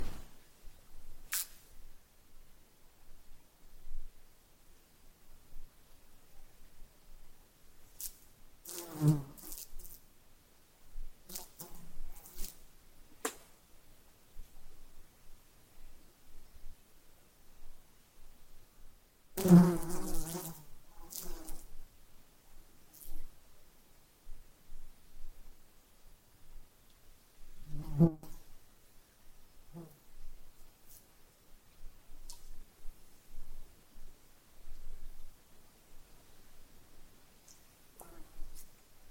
营地 " 苍蝇在靠近麦克风的地方嗡嗡作响
描述：靠近mic.flac飞嗡嗡声
Tag: 嗡嗡声 周围 关闭